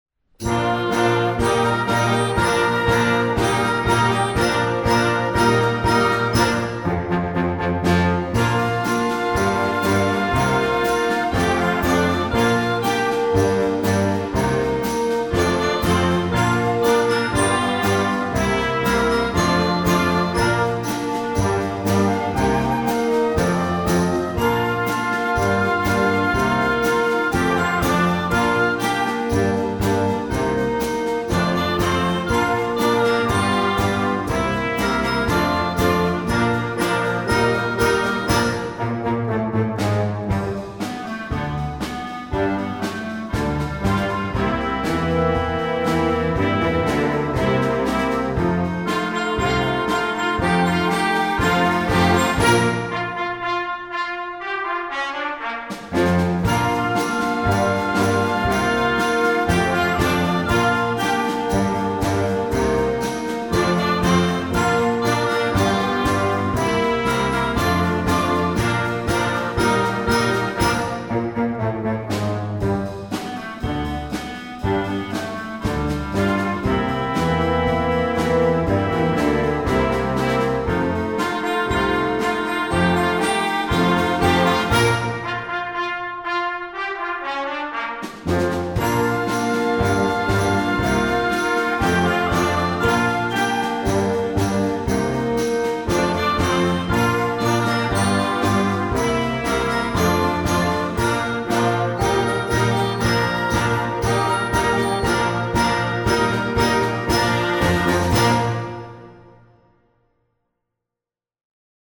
pop, rock, concert, instructional, children